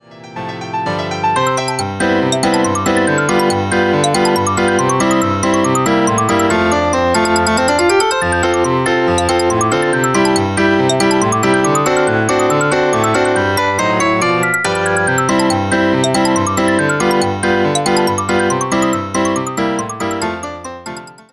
All works are written for xylophone and piano.
a concert piece for xylophone and piano